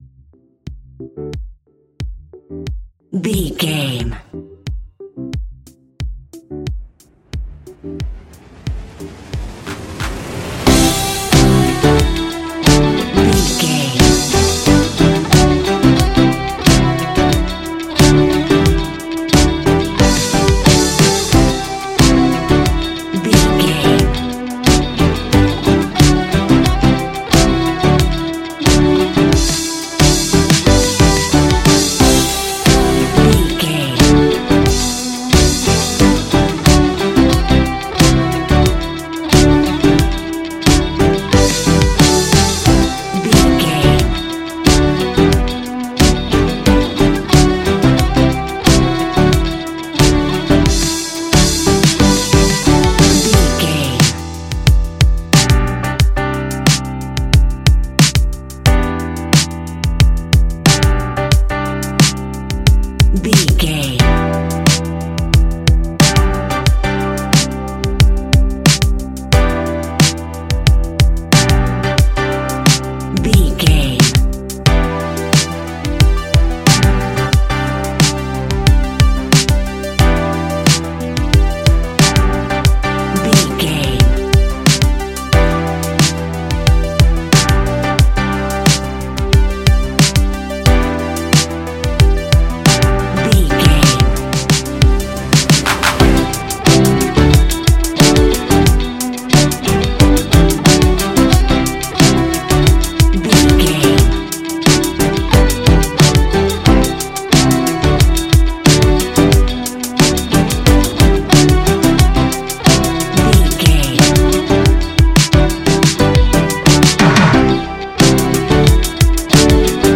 Ionian/Major
ambient
electronic
chill out
downtempo
pads